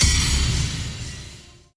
MH - Menu Click 3 (MH3U).wav